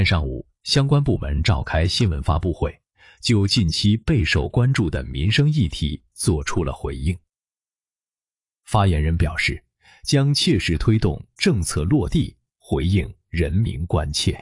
小米放出的官方案例，用严肃、字正腔圆的新闻播报声线，复刻了《康熙微服私访记》中的一段经典台词，极具反差感。